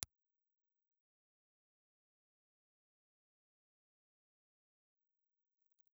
Ribbon
Impulse Response file of the Xaudia Beeb microphone.
Reslo_Xaudia_Beeb_IR.wav